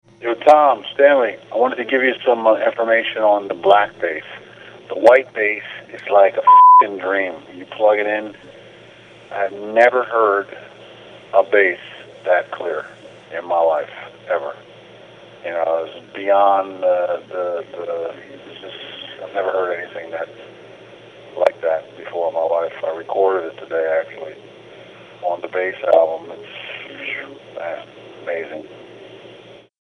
*Click “Play” button above to hear Stanley critique the White Sibling Bass*
Stanley-Clarke-reacts-to-Spellbinder-White-Sibling.mp3